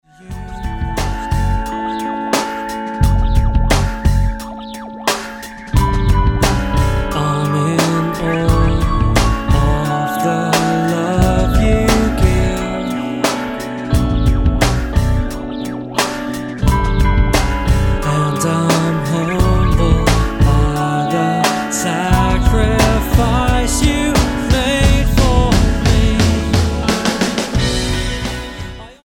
Roots/Acoustic
Praise & Worship